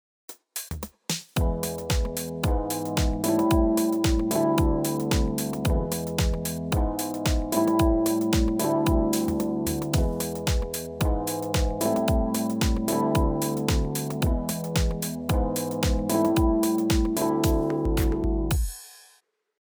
先ほどのサウンドをより心地よいエレピサウンドにするために調整してみましょう。
• Channnel・・・ディレイは少なめでバランスをそれぞれ左右に目一杯広げました。
• Phase・・・あんまり深く下世話な感じにならないんですね。
拡がりがありつつ艶のある感じでヘッドフォンで聴くと左手のフレーズが耳のすぐ後ろで鳴っているような感じがしませんか？